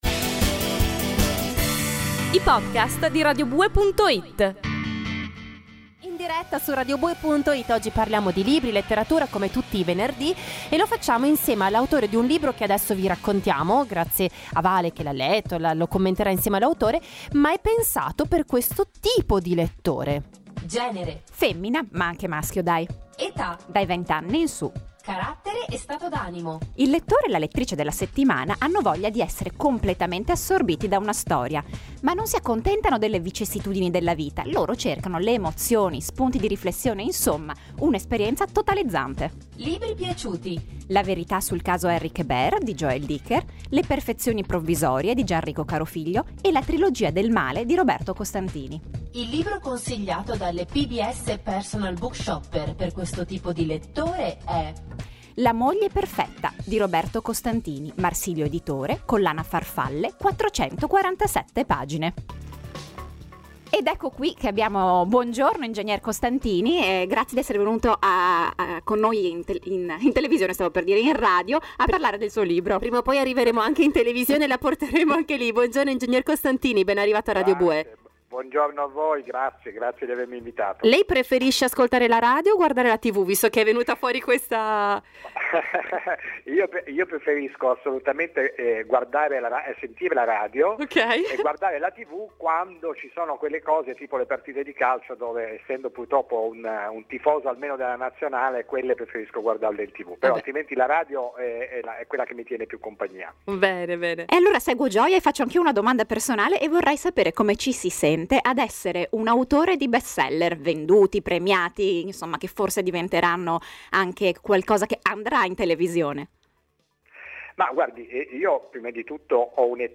La moglie perfetta, intervista a Roberto Costantini
Al telefono con Roberto Costantini, rompiamo il ghiaccio chiedendogli senza remore come ci si senta ad aver raggiunto il successo, e la risposta un poco ci stupisce.